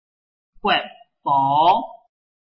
海陸腔-變調學習
規則2：陰入聲變調
即陰入調（vd 5）後面不論接什麼調時，多變為陽入調（vdˋ 2）。
國寶 gued boˊ
guedˋ boˊ